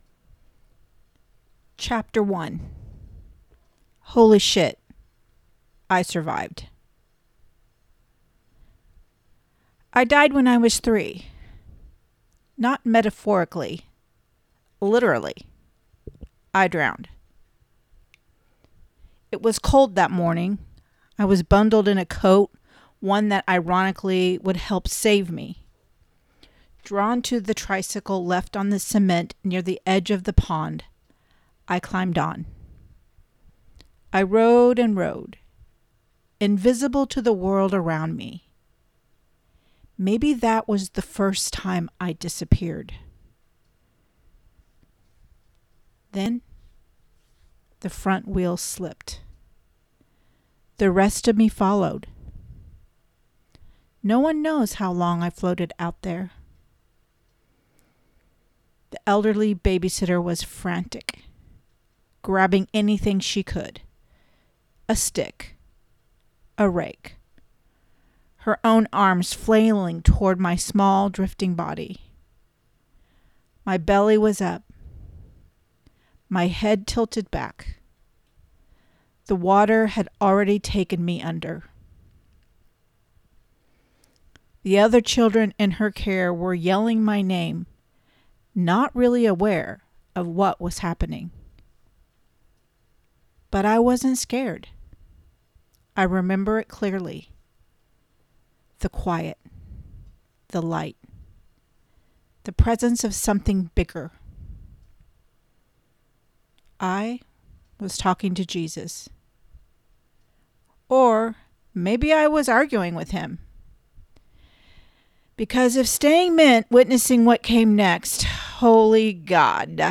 Sample Audio Chapter One